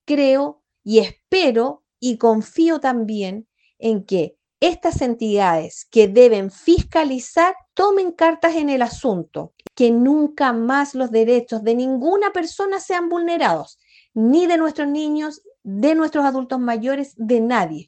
Ante estos antecedentes, la concejala de Pucón, Marina Matus, confirmó que recibió información de otros casos similares, incluidos testimonios de exfuncionarias del recinto, lo que la llevó a presentar una denuncia formal ante la Fiscalía de Pucón.